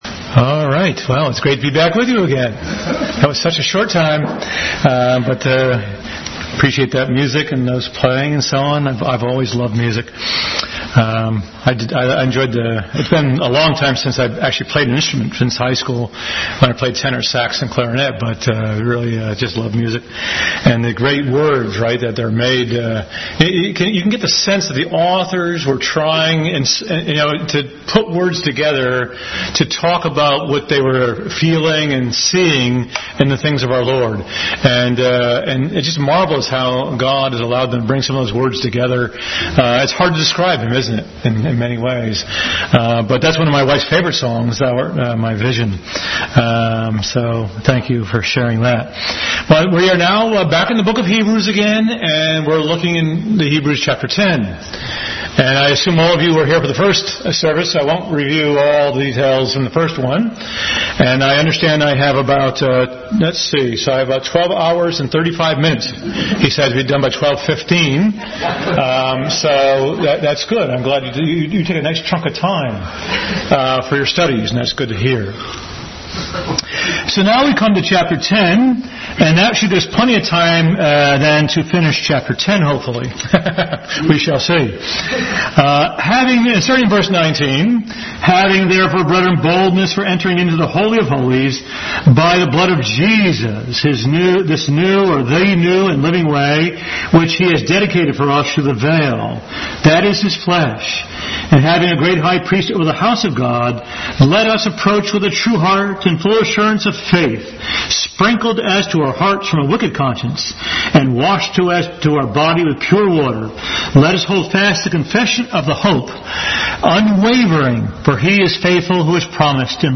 Hebrews 10:19-39 Passage: Hebrews 10:19-39 Service Type: Family Bible Hour Bible Text